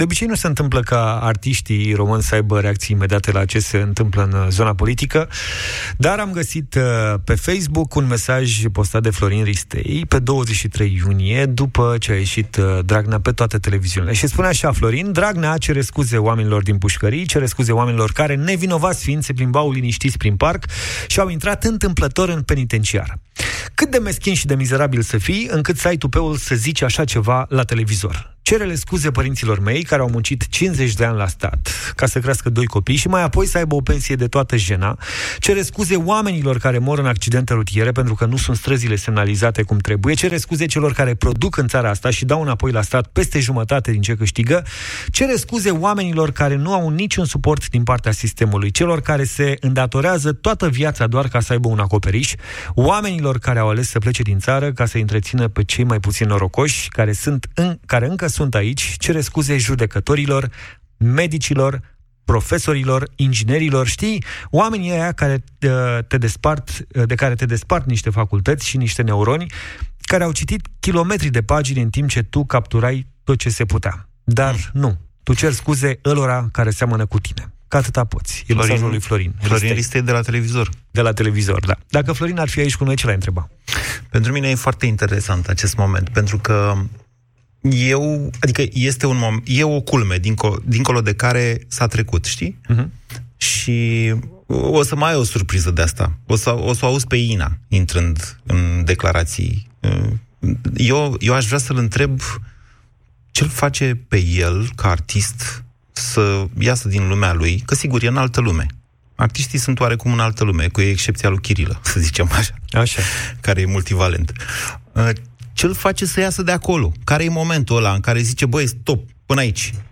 George Zafiu și Moise Guran au căutat să afle de la Florin Ristei ce l-a determinat să ia atitudine, în mod public.
”De ce mai stau eu aici?”, s-a întrebat, retoric, Florin Ristei, în direct la Europa FM, în Deșteptarea.